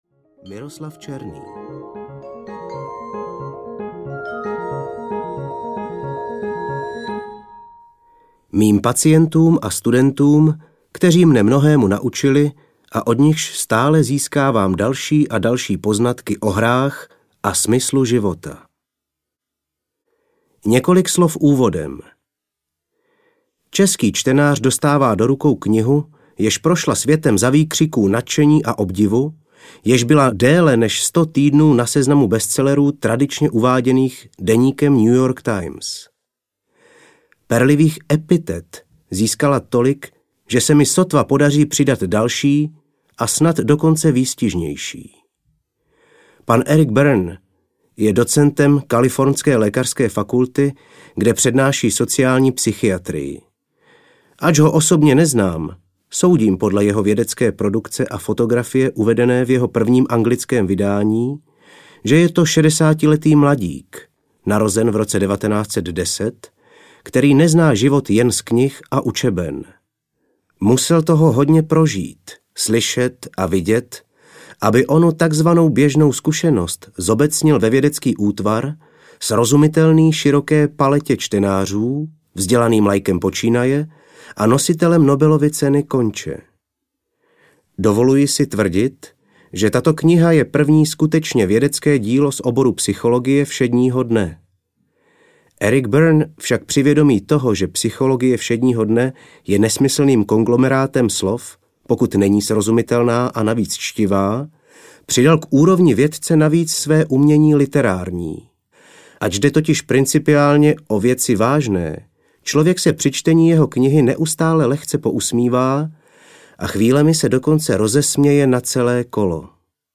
Jak si lidé hrají audiokniha
Ukázka z knihy